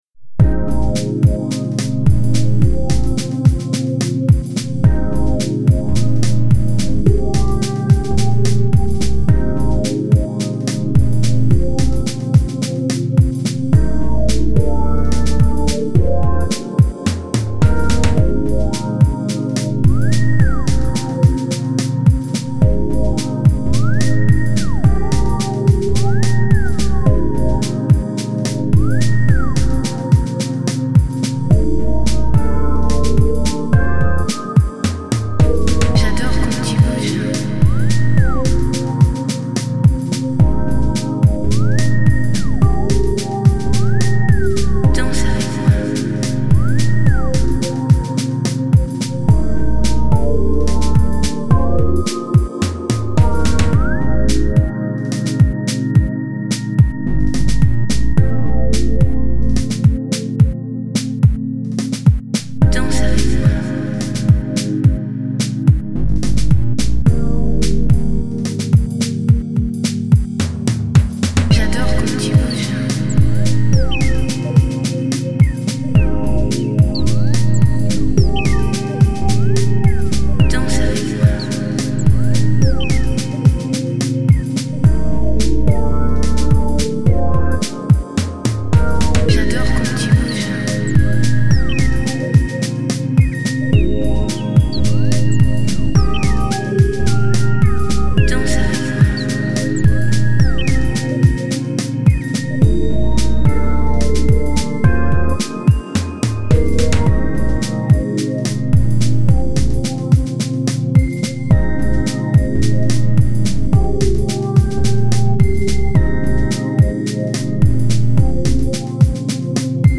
Chillout